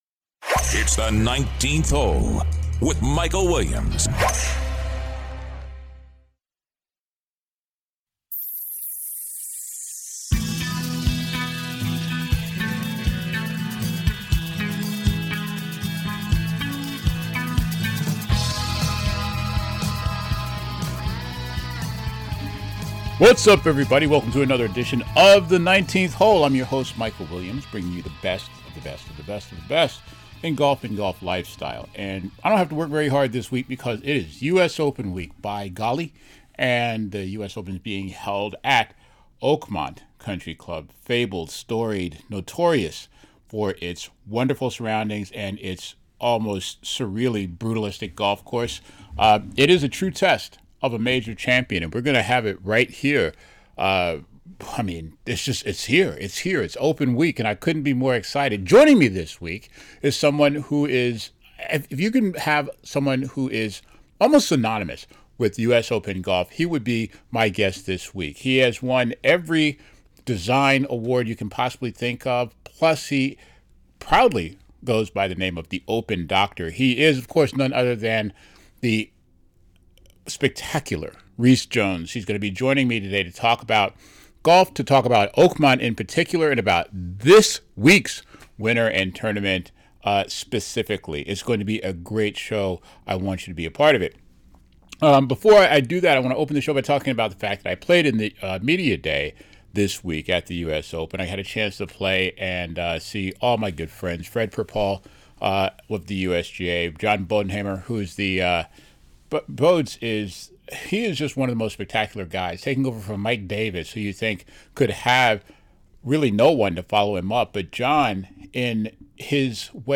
His special guest is the acclaimed golf course archtecht "The Open Doctor", Rees Jones, who gives history, insight and his prediction for the winner and the winning score!